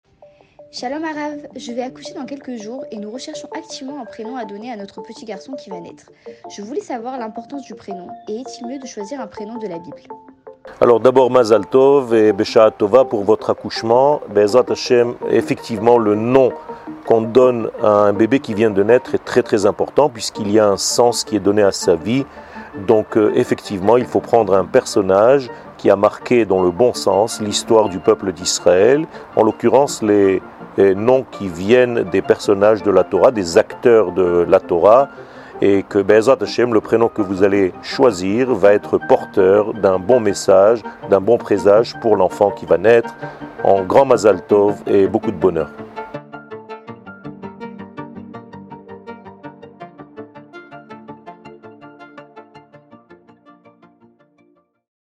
שיעור מ 13 אוקטובר 2022
שיעורים קצרים